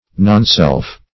Meaning of nonself. nonself synonyms, pronunciation, spelling and more from Free Dictionary.
nonself.mp3